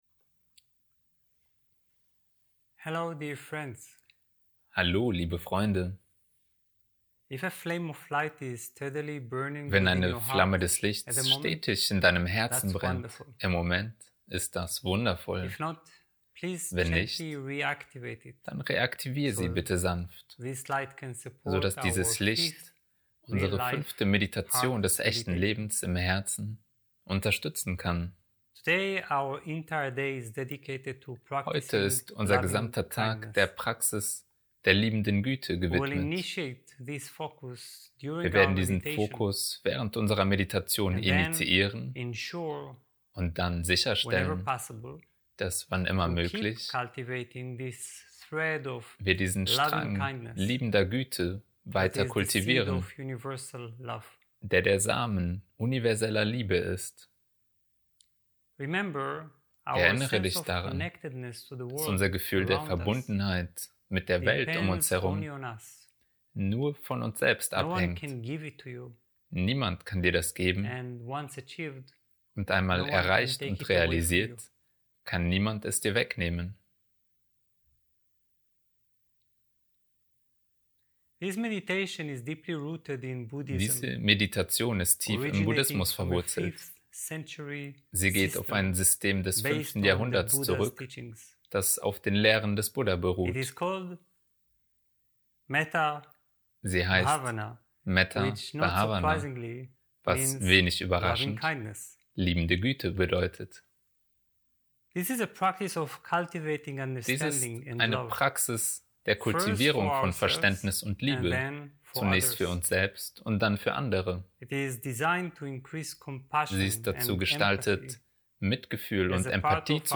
Einführung auf deutsch